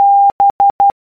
Letters B, b
B_morse_code.ogg.mp3